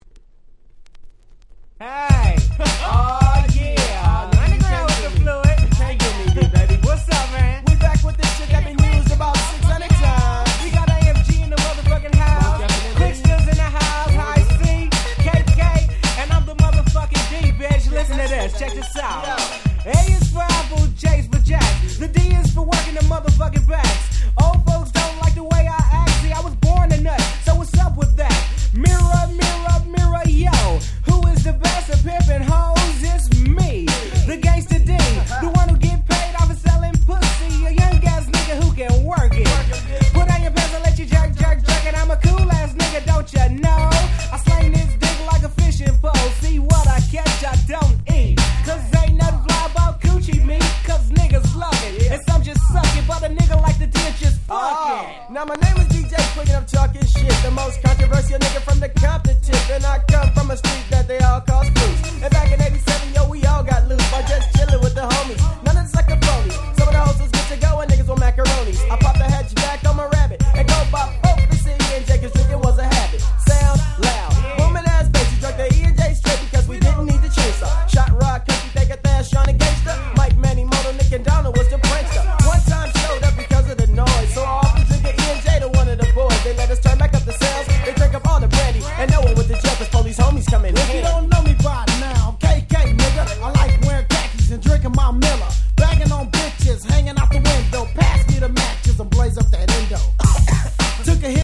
92' Smash Hit West Coast Hip Hop !!